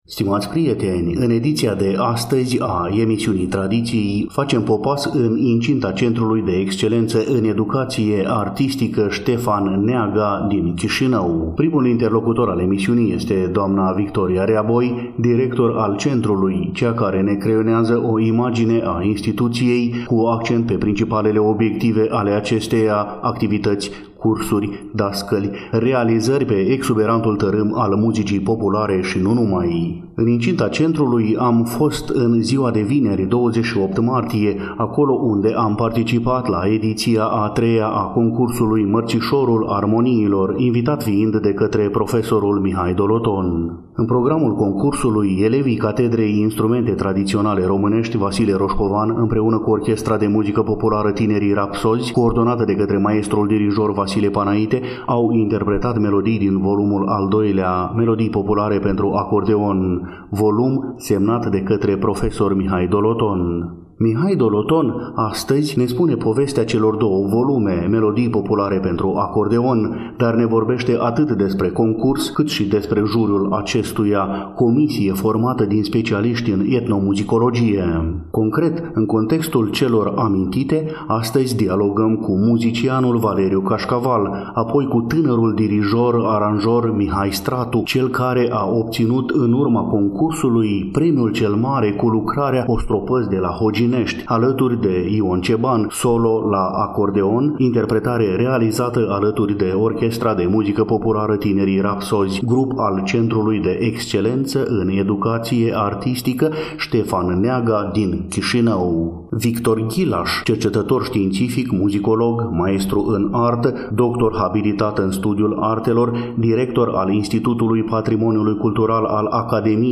În ediția de astăzi a emisiunii Tradiții, facem popas în incinta Centrului de Excelență în Educație Artistică „Ștefan Neaga” din Chișinău.